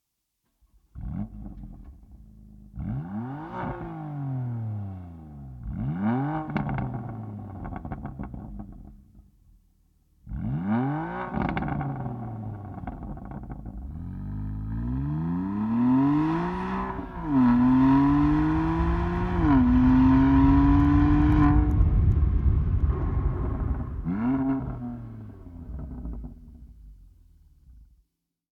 Stock-exhaust-BMW-M2-Competition.wav